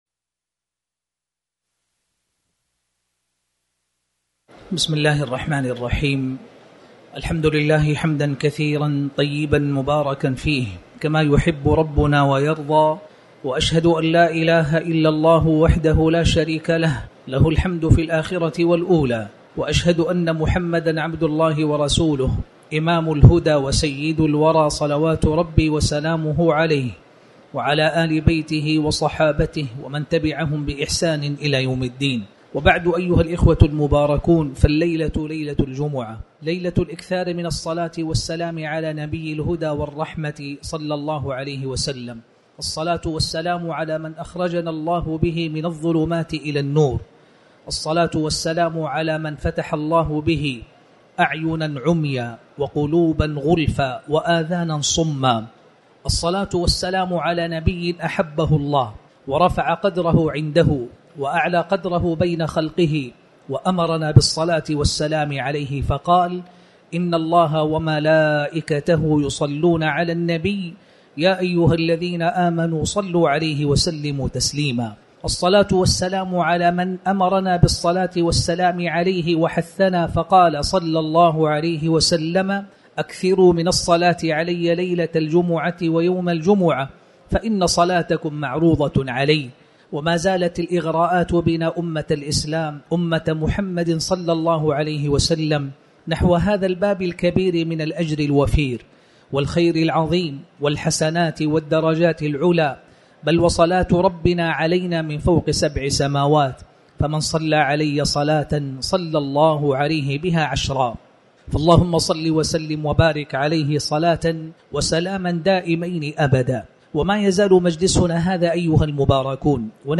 تاريخ النشر ٩ جمادى الآخرة ١٤٤٠ هـ المكان: المسجد الحرام الشيخ